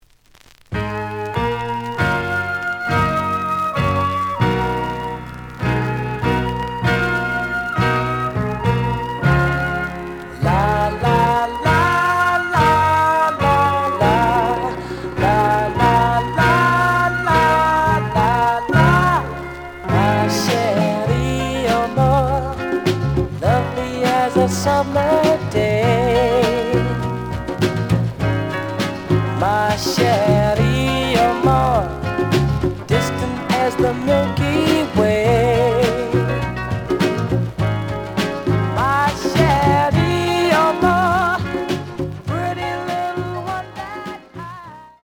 The audio sample is recorded from the actual item.
●Genre: Soul, 60's Soul
Slight noise on A side.